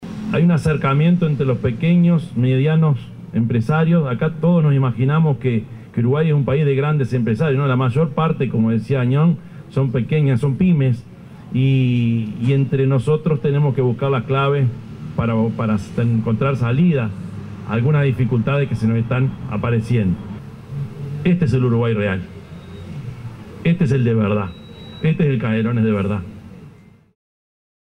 Con la presencia del Intendente de Canelones, Yamandú Orsi, de directivos de la Unión de Vendedores de Nafta del Uruguay (UNVENU) y representantes de las productoras del proyecto Canelones te Alimenta, se realizó el lanzamiento del proyecto UNVENU - Canelones te Alimenta en la Estación ANCAP de Lagomar, Ciudad de la Costa.
yamndu_orsi_intendente_de_canelones.mp3